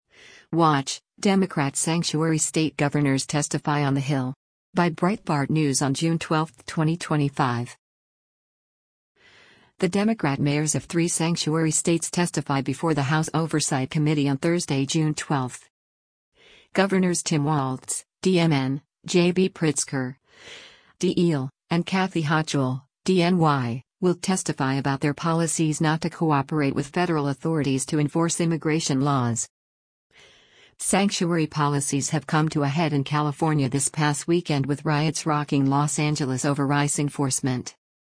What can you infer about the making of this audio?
The Democrat mayors of three sanctuary states testify before the House Oversight Committee on Thursday, June 12.